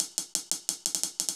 Index of /musicradar/ultimate-hihat-samples/175bpm
UHH_AcoustiHatA_175-05.wav